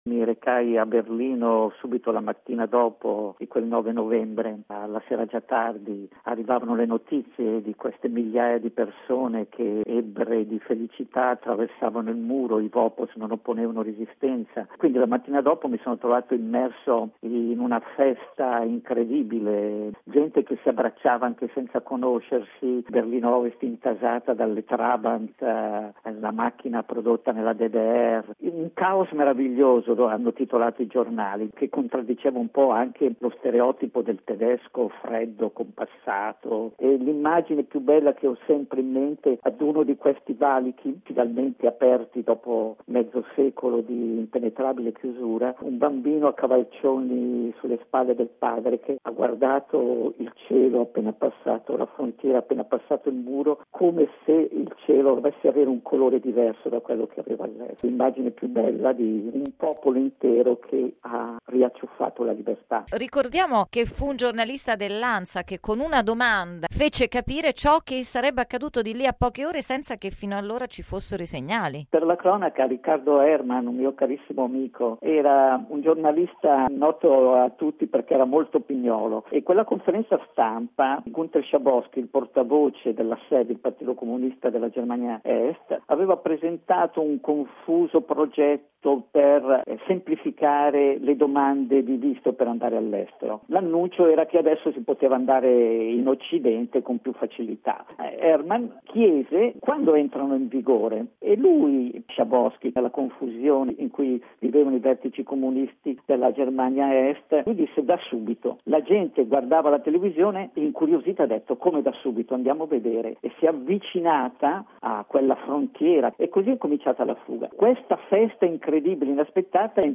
Muro di Berlino.